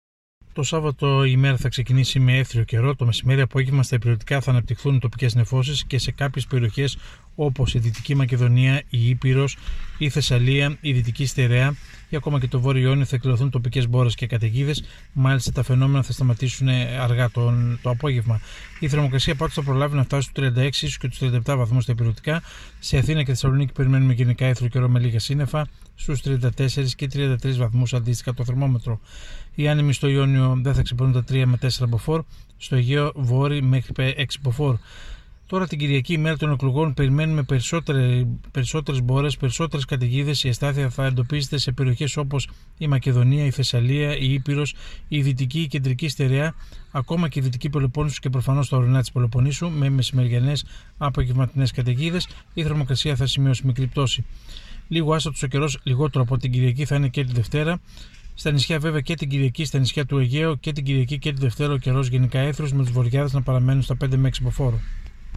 Ο μετεωρολόγος